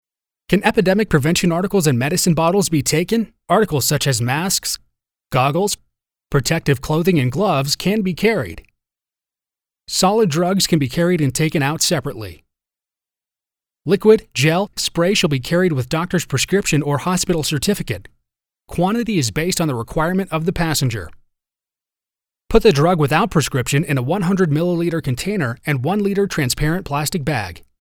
美式英语中年低沉 、激情激昂 、大气浑厚磁性 、沉稳 、娓娓道来 、科技感 、积极向上 、时尚活力 、神秘性感 、素人 、脱口秀 、男专题片 、宣传片 、纪录片 、广告 、飞碟说/MG 、课件PPT 、工程介绍 、绘本故事 、动漫动画游戏影视 、旅游导览 、微电影旁白/内心独白 、60元/百单词男英111 美式英语 China SA_3 低沉|激情激昂|大气浑厚磁性|沉稳|娓娓道来|科技感|积极向上|时尚活力|神秘性感|素人|脱口秀